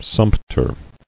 [sump·ter]